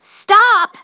synthetic-wakewords
ovos-tts-plugin-deepponies_Starlight_en.wav